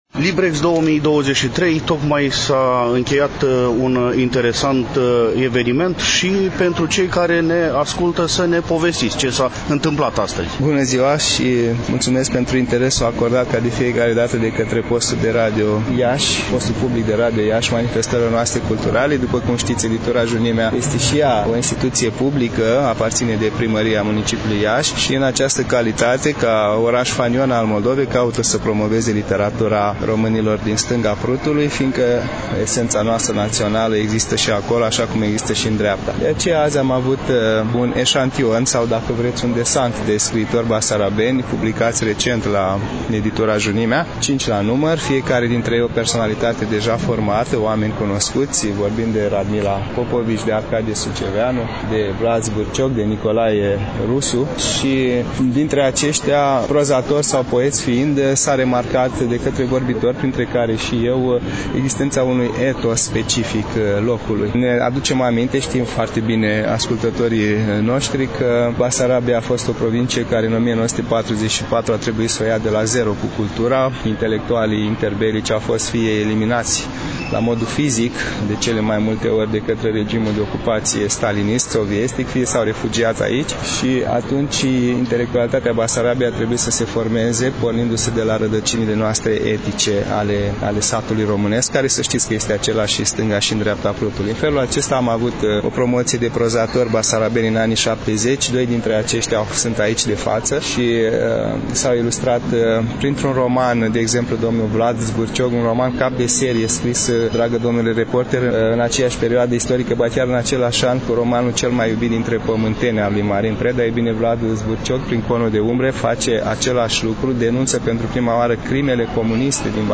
În emisiunea de astăzi, relatăm de la Târgul de carte LIBREX 2023, manifestare desfășurată, la Iași, în incinta Palas Mall, în perioada 10 – 14 mai.